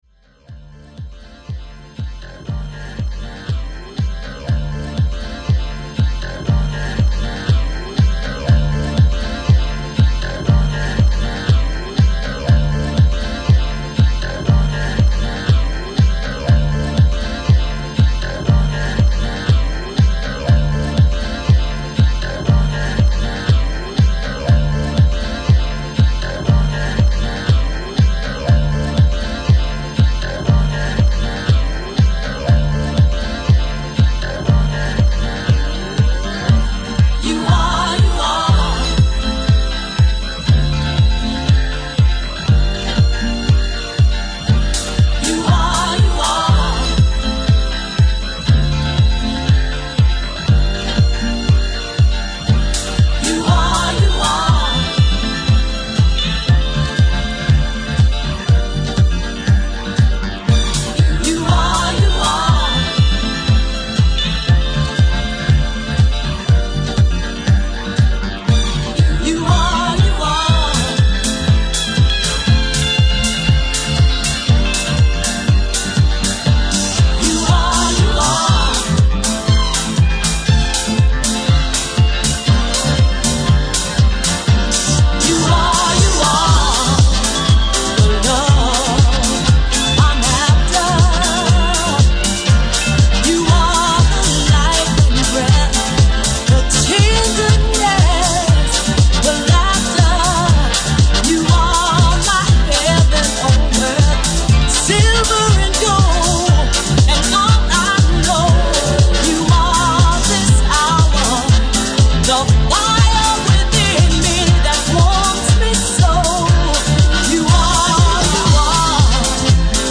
edits galore